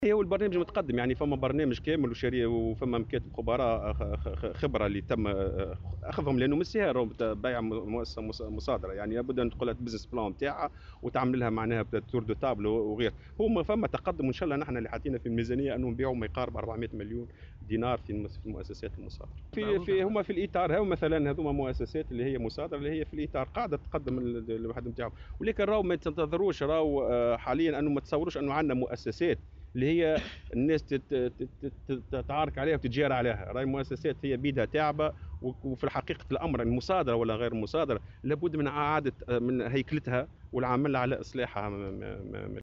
وأوضح في تصريح اليوم لمراسلة "الجوهرة أف أم" على هامش ندوة تتعلق بالشروع في تنفيذ مخطط حوكمة المنشآت و المؤسسات العمومية، أن عديد المؤسسات المصادرة تعيش أوضاعا صعبة، قائلا " ما تتصوروش انو فمة إقبال على شراء هذه المؤسسات".وأضاف أنه لابد من إعادة هيكلتها والعمل على إصلاحها.